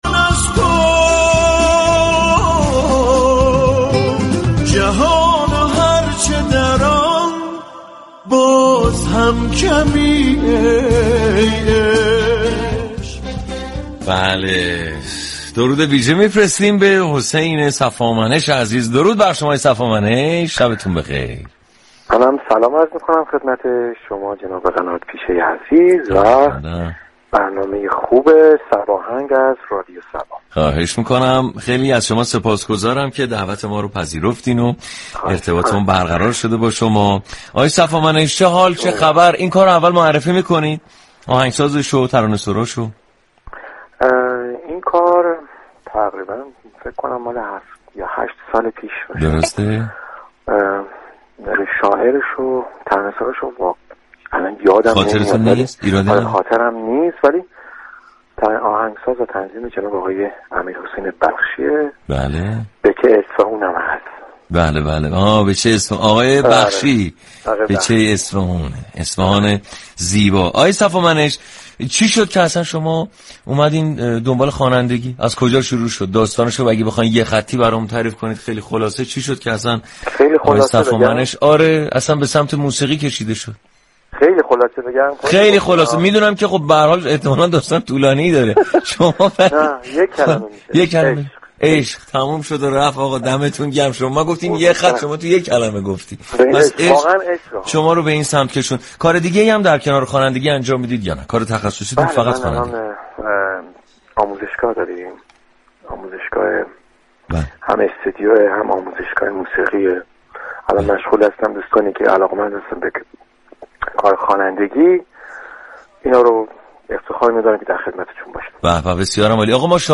به گزارش روابط عمومی رادیو صبا، «صباهنگ» برنامه موسیقی محور رادیو صبا است كه با پخش ترانه های درخواستی، فضای شادی را برای مخاطبان این شبكه ایجاد می كند.